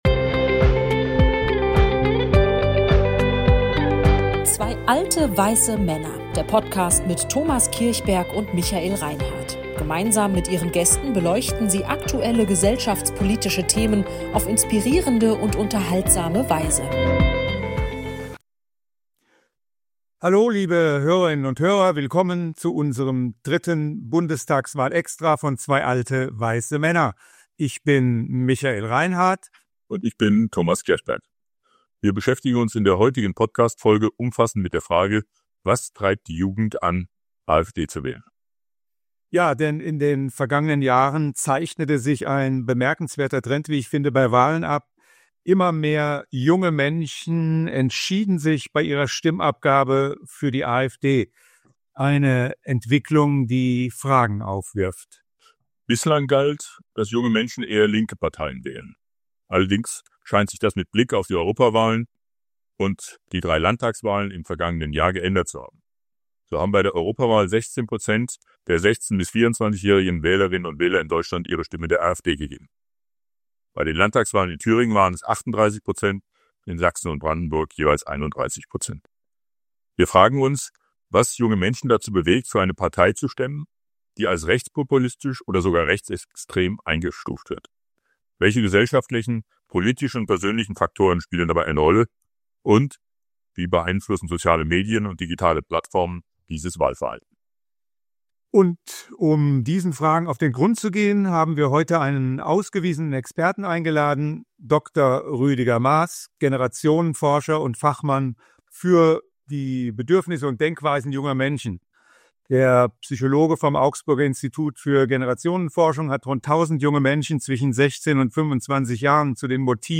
Ein aufschlussreiches Gespräch über die Herausforderungen unserer Demokratie, das ihr nicht verpassen solltet!